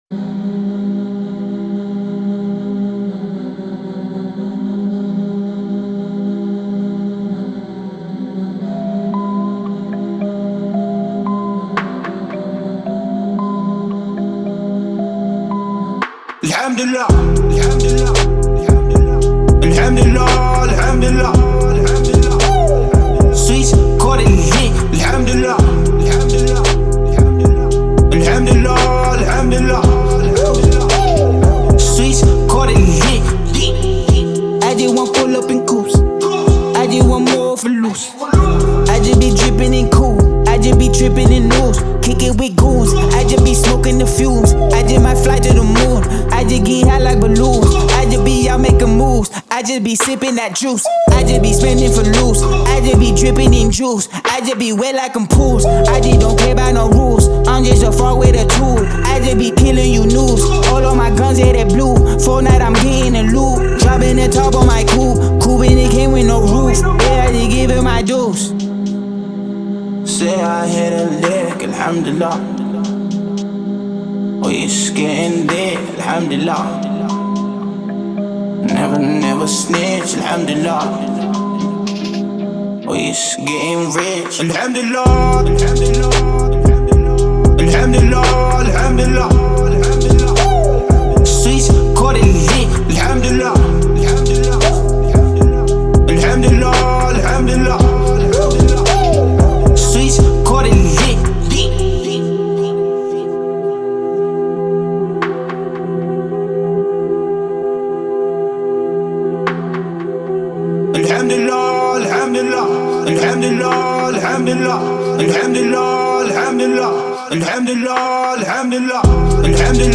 موزیگ عربی
رپ